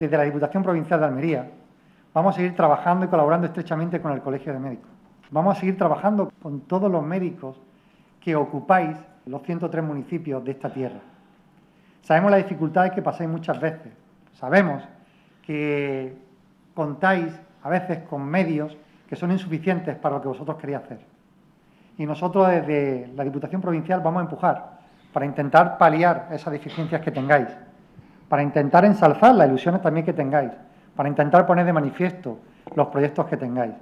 El presidente de Diputación clausura el acto de celebración de la patrona del Colegio de Médicos - Blog Diputación de Almería